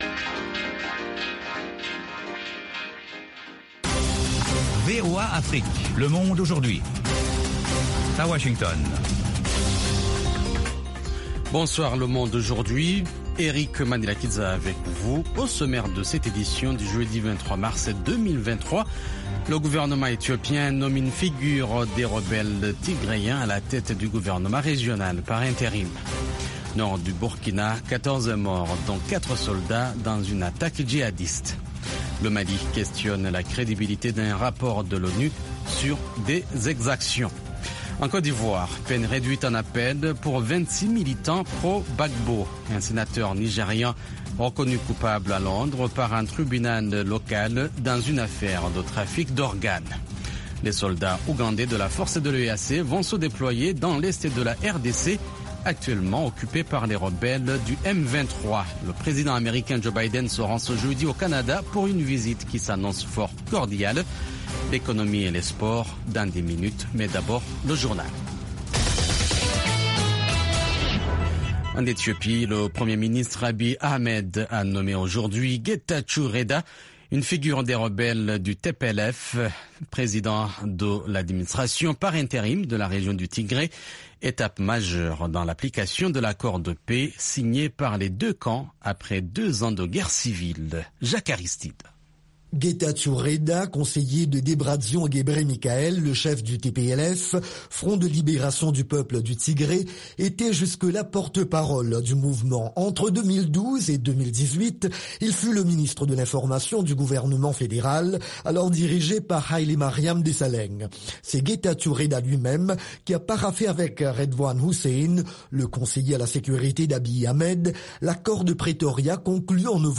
Le programme phare du service francophone dure 30 minutes sur les informations de dernières minutes, des reportages de nos correspondants, des interviews et analyses sur la politique, l’économie, les phénomènes de société et sur la société civile.